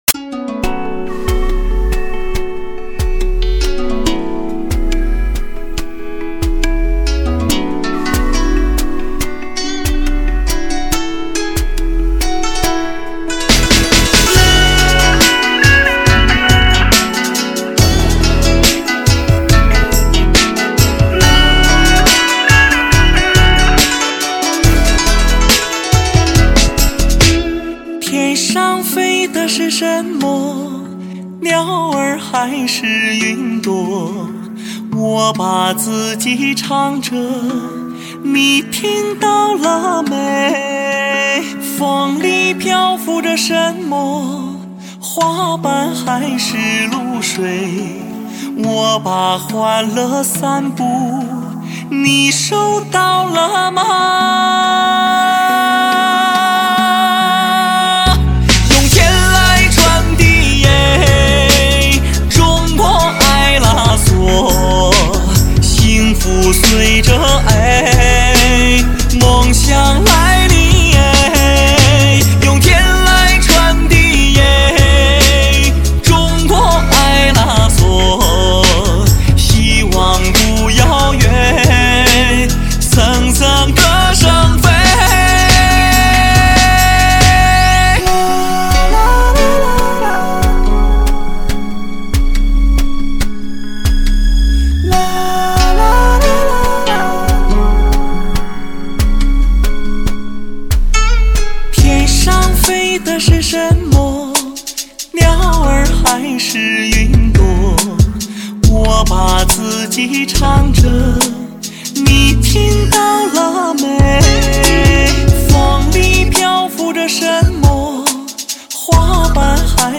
流行与发烧的新慨念，时尚民族风的新体验。
草原时尚节奏风强势来袭！